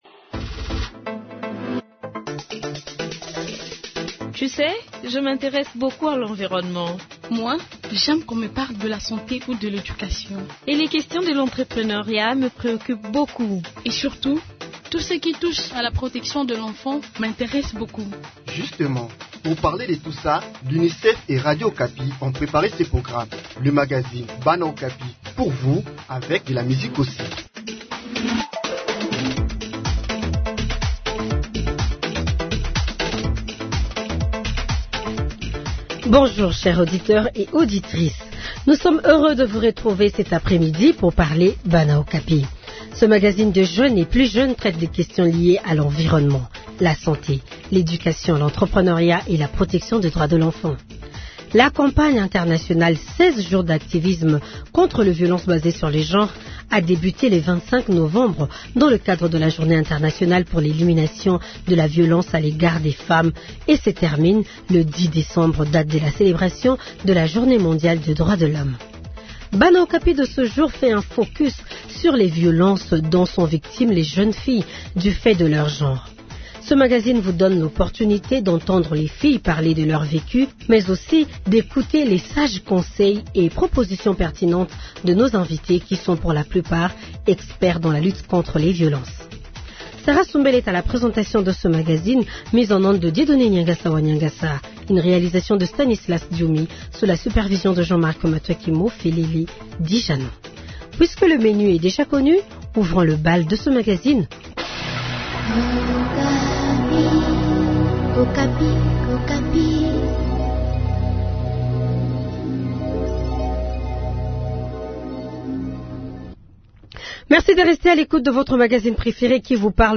Ce magazine vous donne l’opportunité d’entendre les filles parler de leur vécu mais aussi d’écouter les sages conseils et propositions pertinentes de nos invités, qui sont pour la plupart, experts dans la lutte contre les violences.